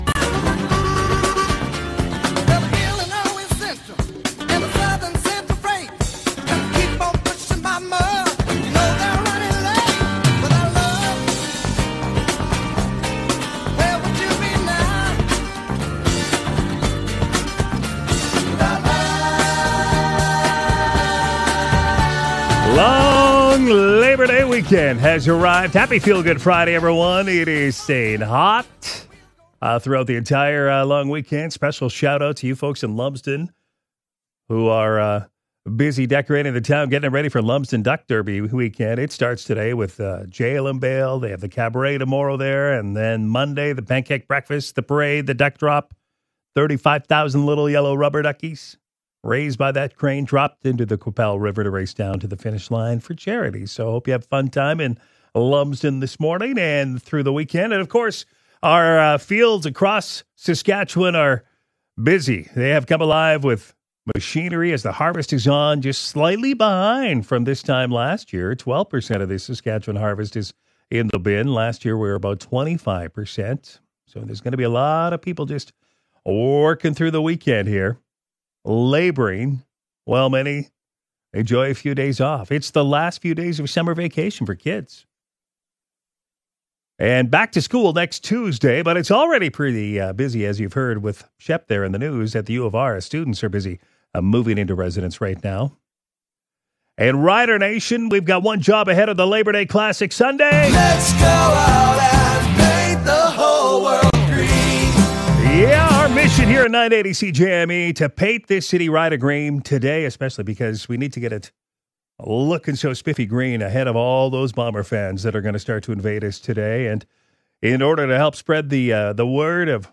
More texts and callers weigh in too.